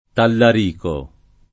[ tallar & ko ]